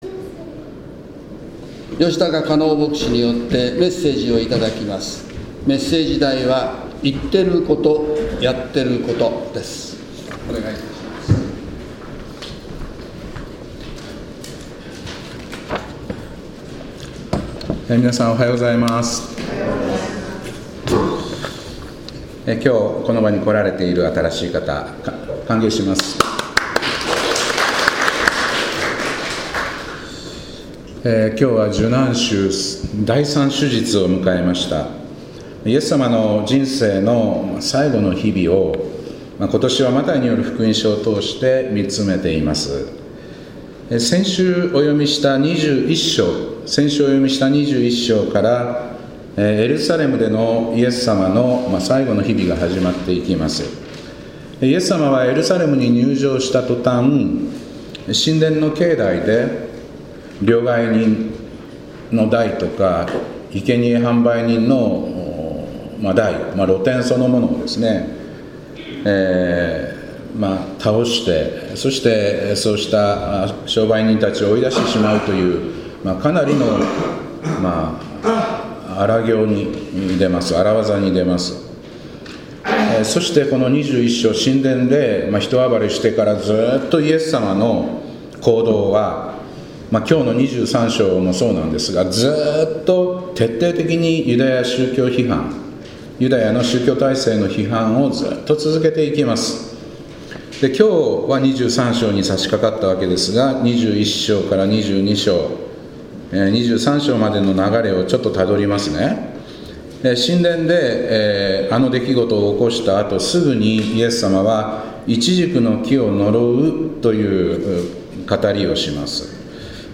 2025年3月23日礼拝「言ってること、やってること」